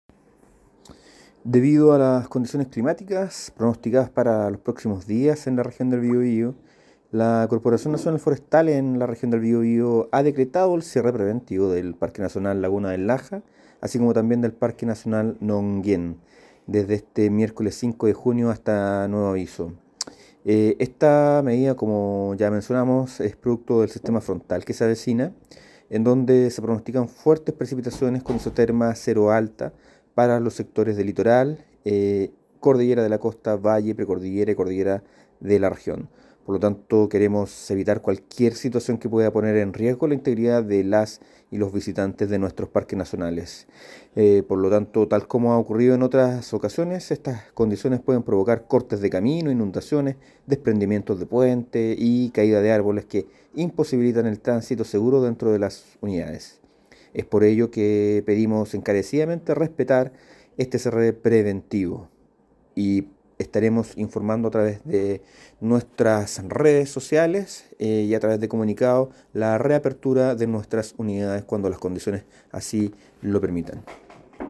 Director regional de CONAF Biobío, Rodrigo Jara.